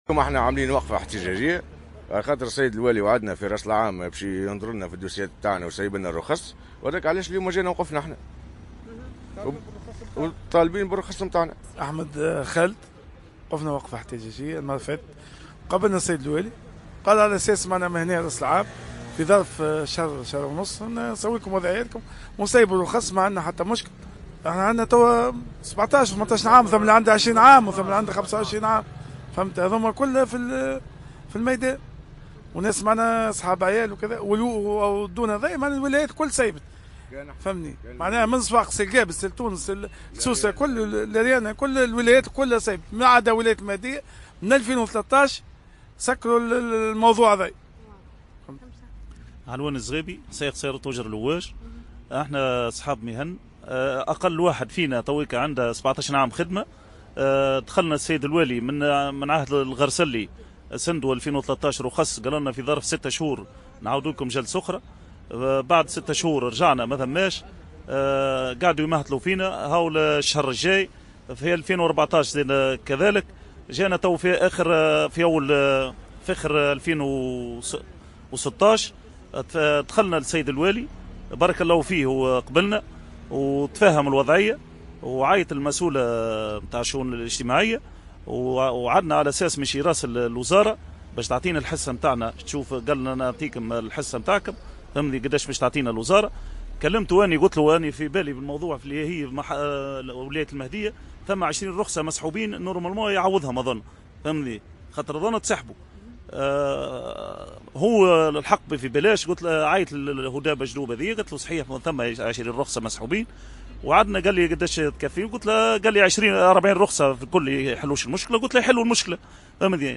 نظم سواق سيارات الأجرة "التاكسي" بالمهدية اليوم الأربعاء 25 جانفي 2017 وقفة احتجاجية وذلك للمطالبة بالنظر في ملفاتهم ومنحهم الرخص .